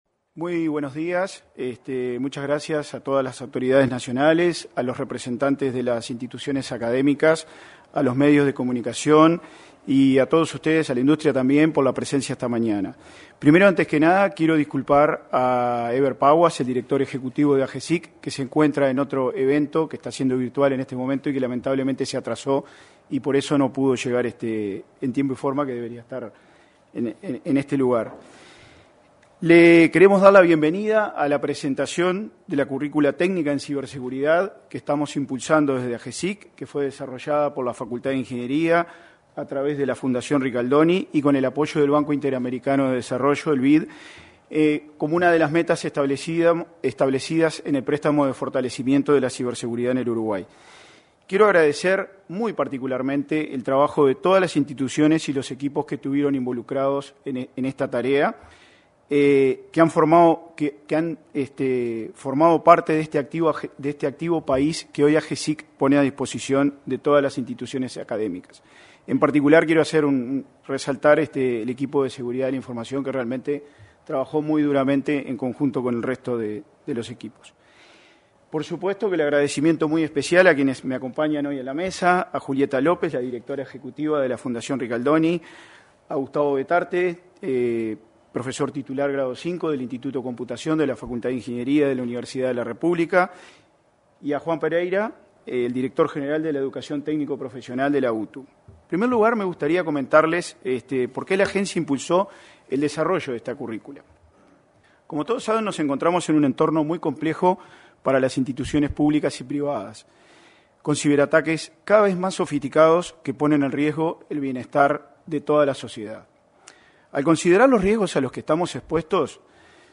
Este miércoles 14, en el salón de actos de la Torre Ejecutiva, se realizó el lanzamiento de la currícula técnica en ciberseguridad.